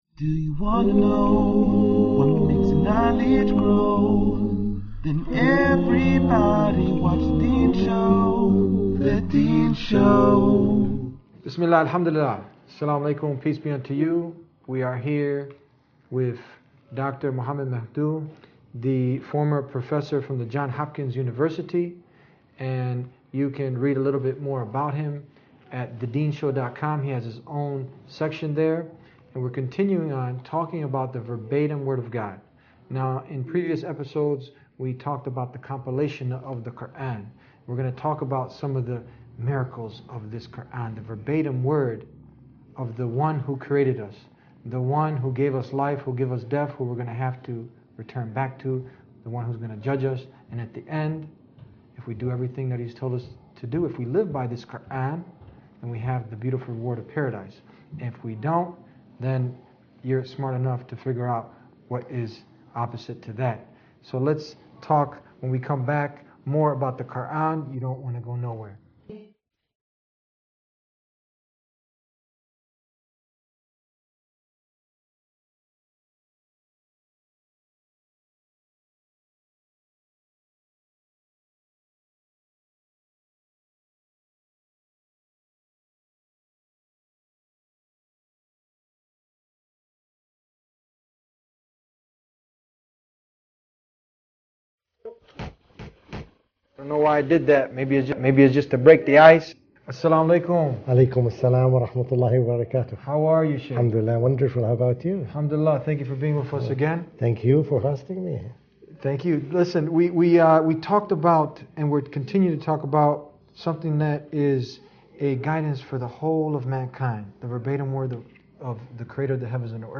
[start time 05:30 ] Recitation/Translation of the first chapter.